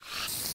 snd_spiderdie.ogg